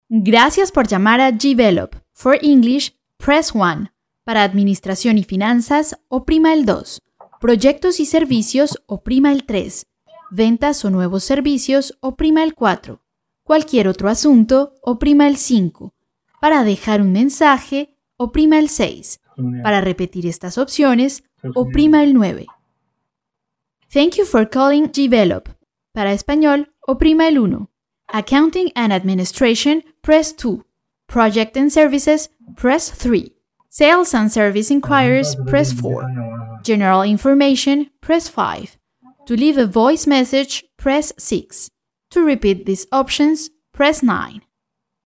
西班牙语女声 电话彩铃 甜美清晰